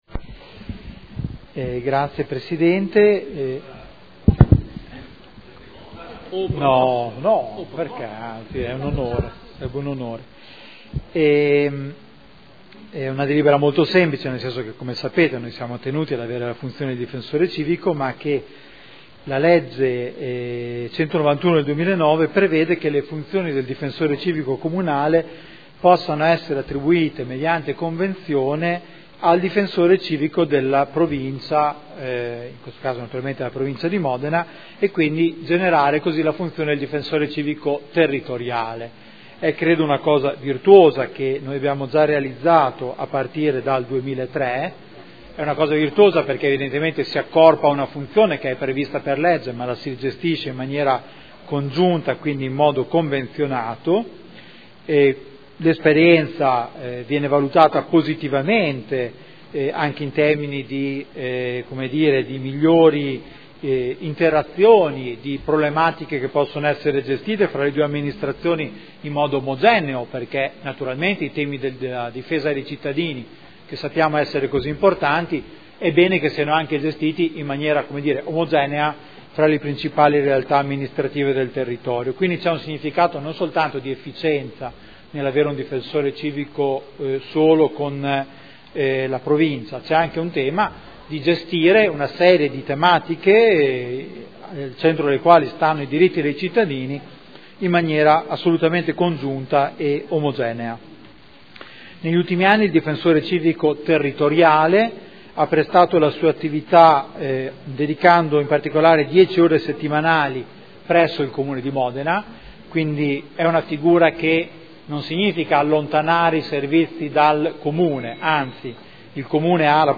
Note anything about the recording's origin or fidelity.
Seduta del 16 gennaio. Proposta di deliberazione: Convenzione per l’utilizzo del Difensore Civico Territoriale – Approvazione